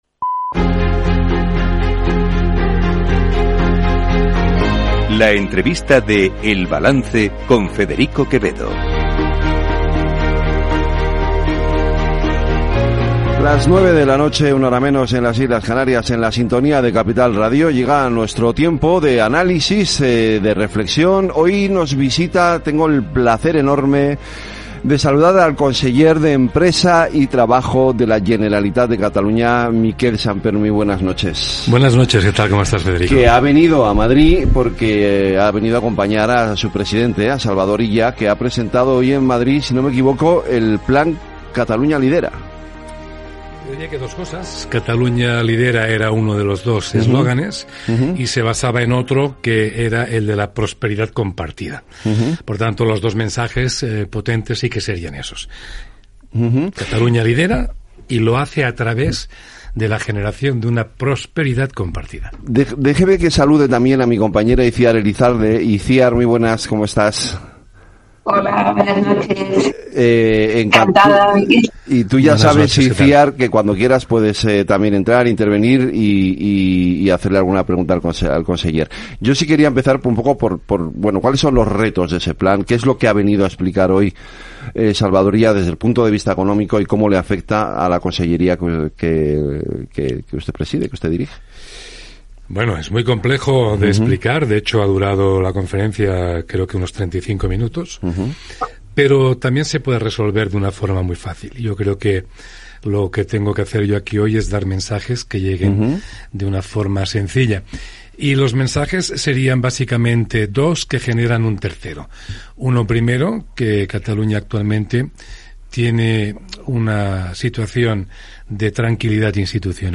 El Balance es el programa informativo nocturno de Capital Radio, una manera distinta, sosegada y reflexiva de analizar la actualidad política y económica